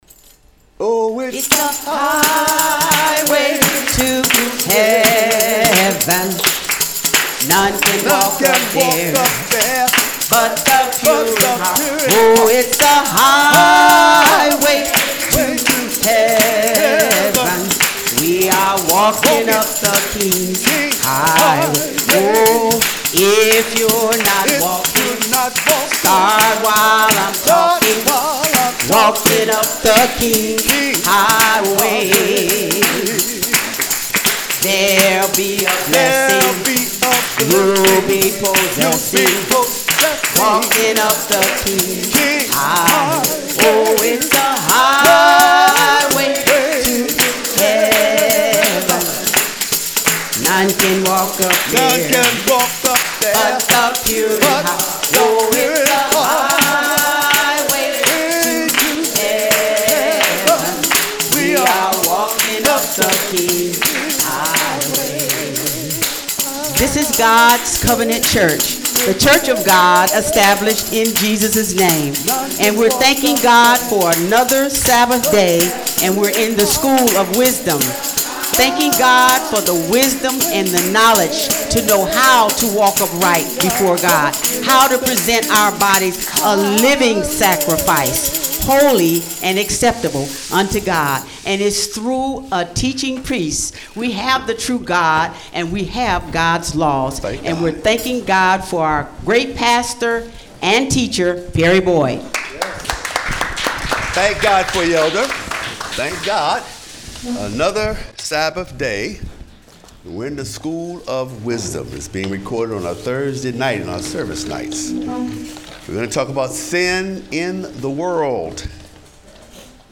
Genre: Gospel.